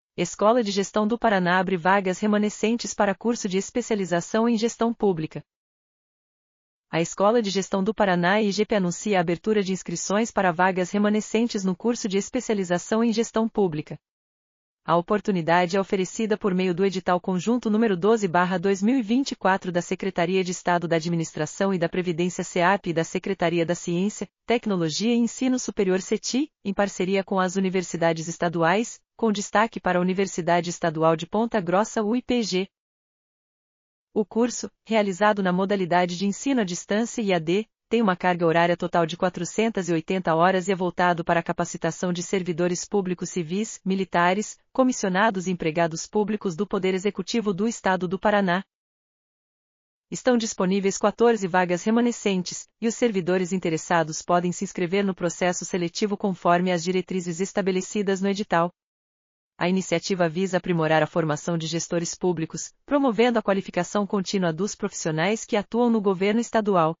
audionoticia_vagas_remanecentes.mp3